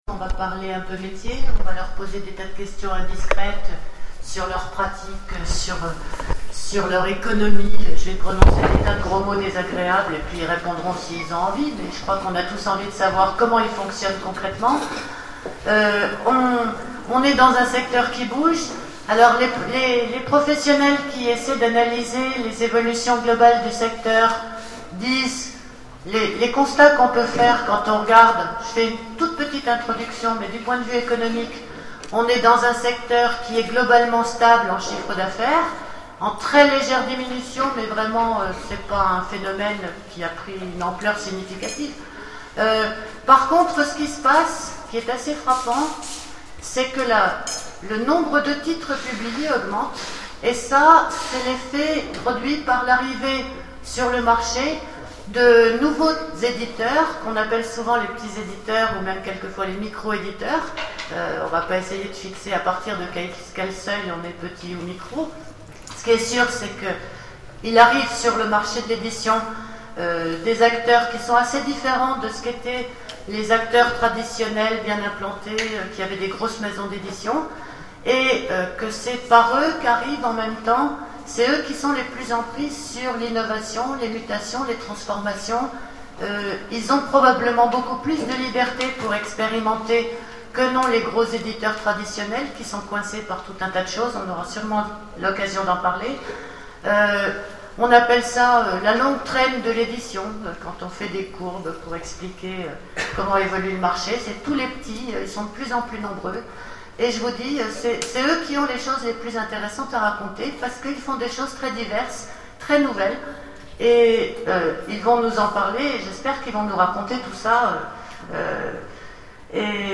Imaginales 2011 : Conférence, les nouvelles formes d'édition et de production